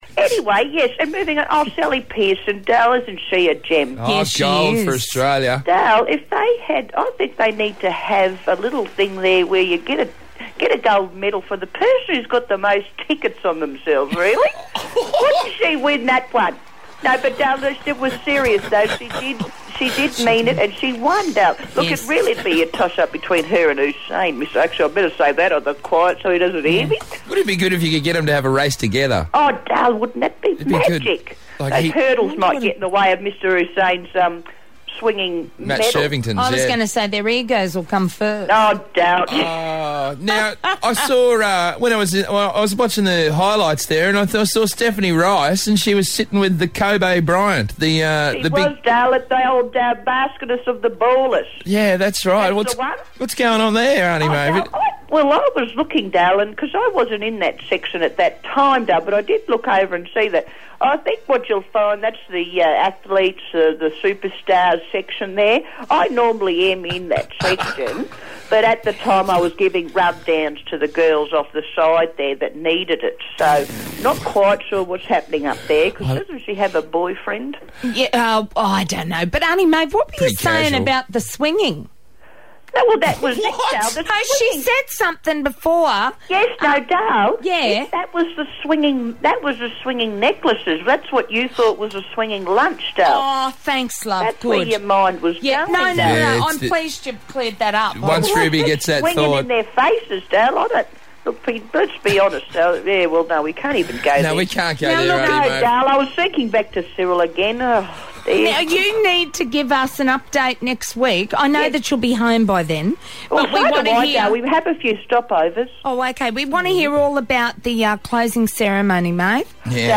Octogenerian reports live from London